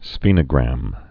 (sfēnə-grăm, sfĕnə-)